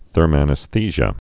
(thûrmăn-ĭs-thēzhə)